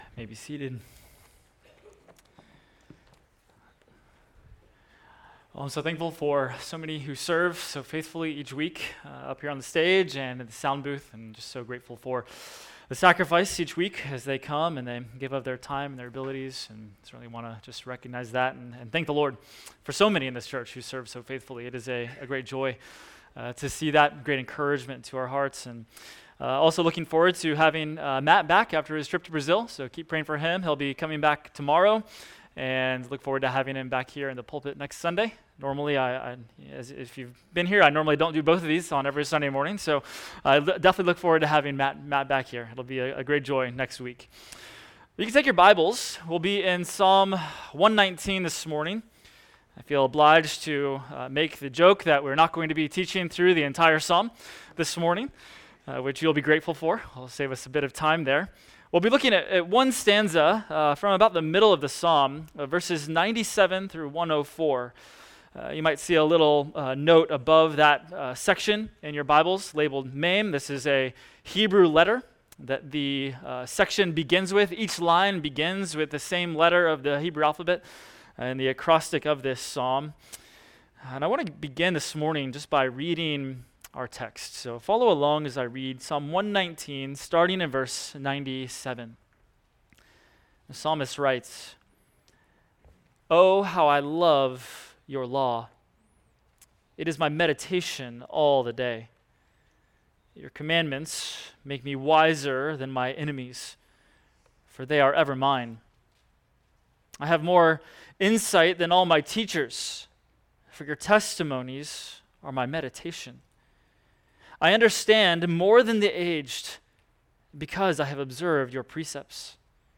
Adult Sunday School – Resolving Conflict – Week 6